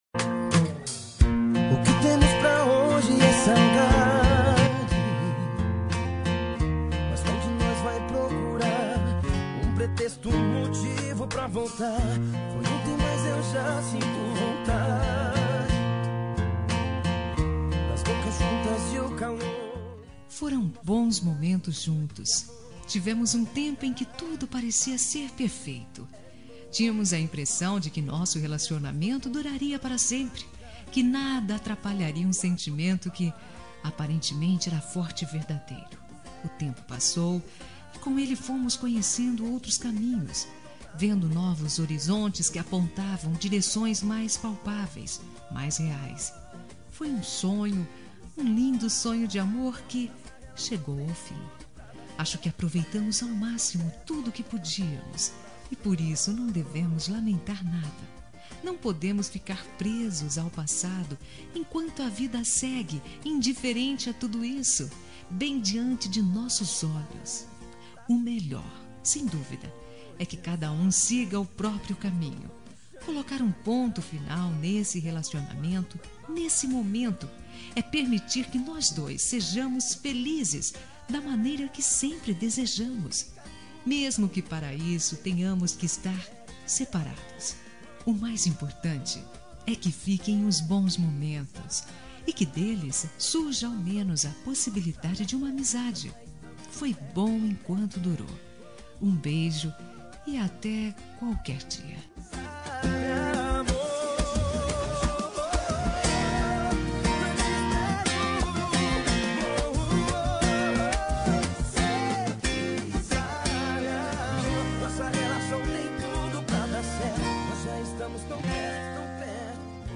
Término – Voz Feminina – Cód: 8662